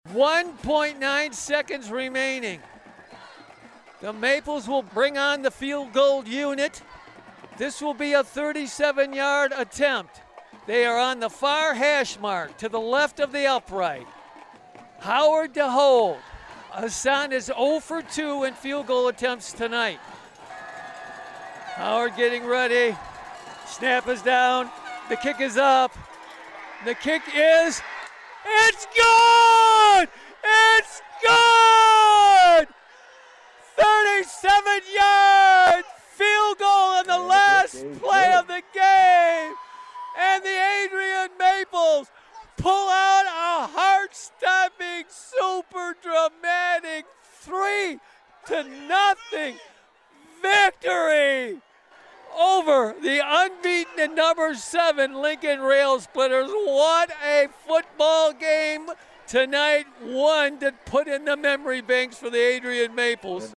It was a walk-off field goal that gave Adrian the dramatic win.
adrian-vs-lincoln-walk-it-off-9-26-25.mp3